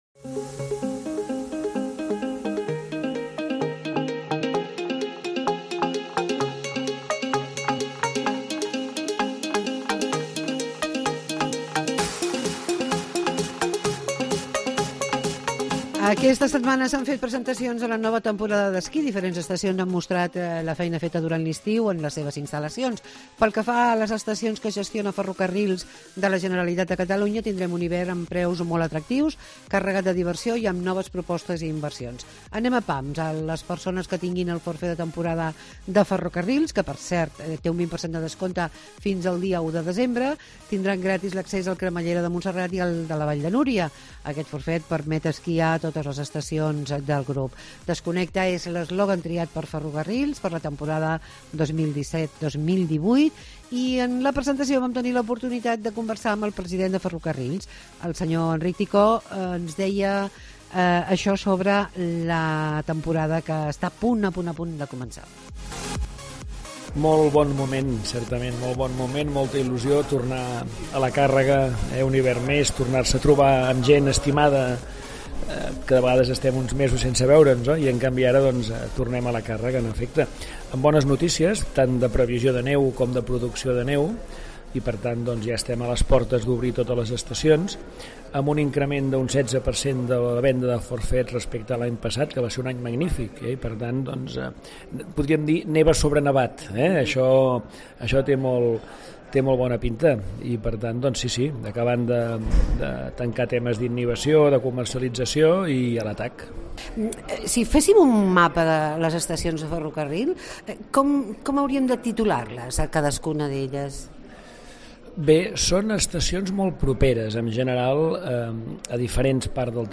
Entrevista amb Enric Ticó, presidente de Ferrocarrils de la Generalitat de Catalunya